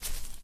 FootstepGrass03.ogg